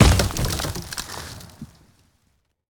car-tree-wood-impact-01.ogg